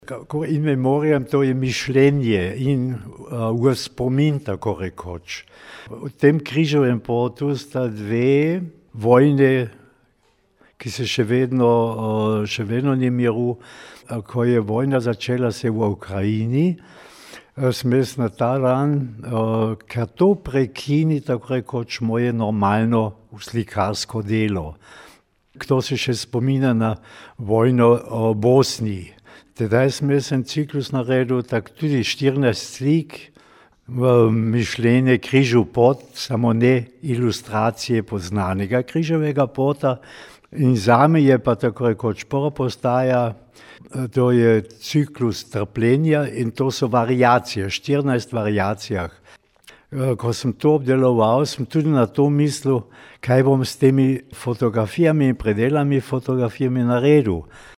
IZJAVA VALENTIN OMAN.mp3